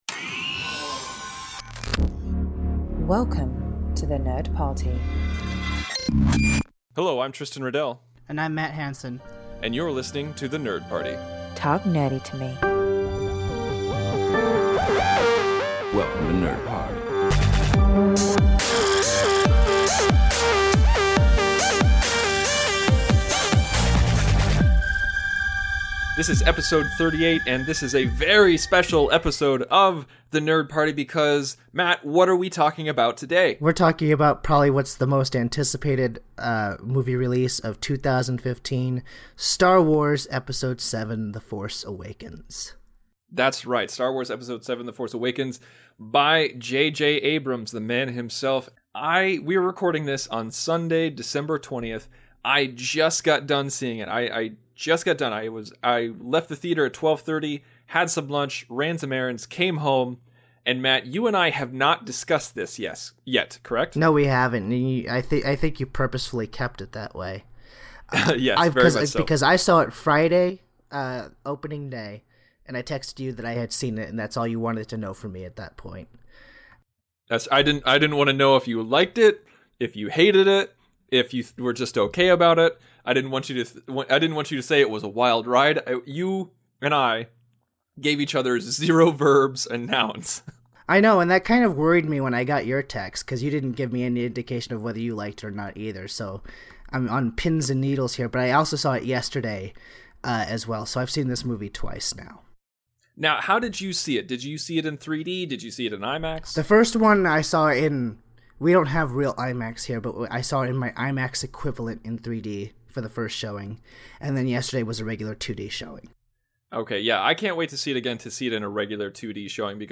Listen to them argue back and forth on the merits and failings of this long awaited revival.